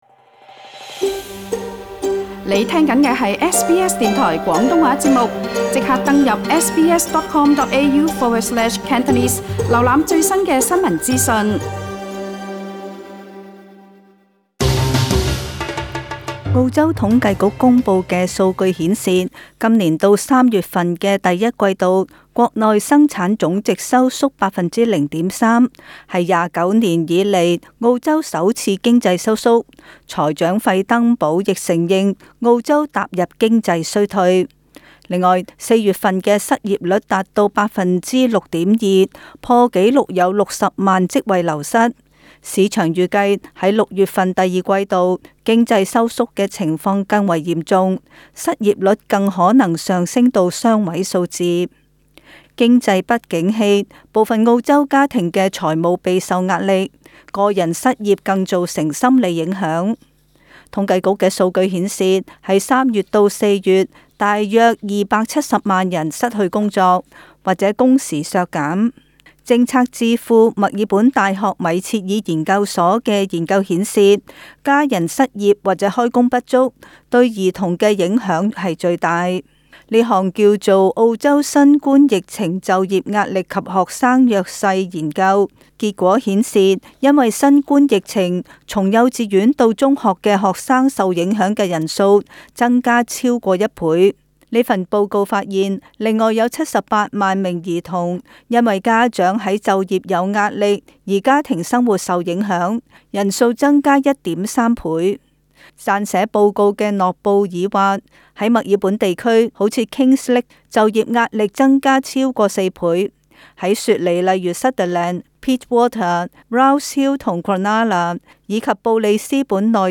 【时事报导】